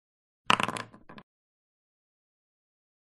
rollSound1.mp3